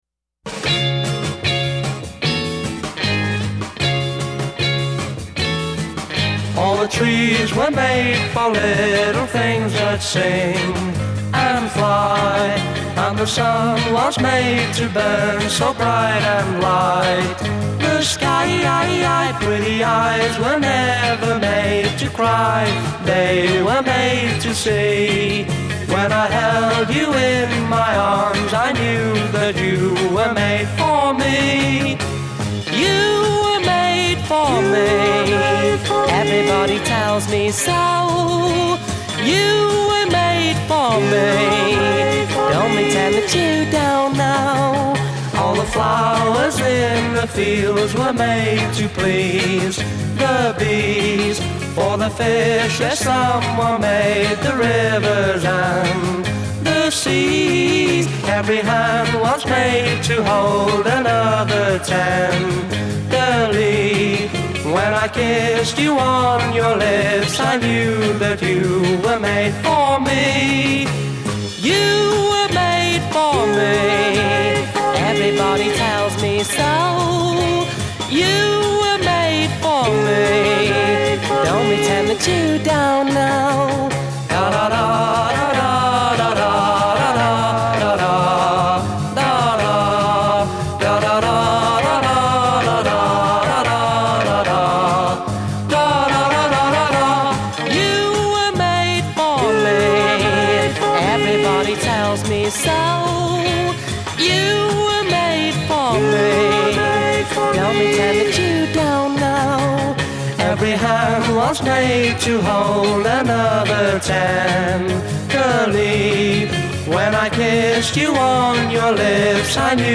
bass guitar
drums
lead guitar
happy tune
intro 0:00 4 guitar chords
B chorus : 8 responsorial chorus c
coda : 2 repeat and build hook f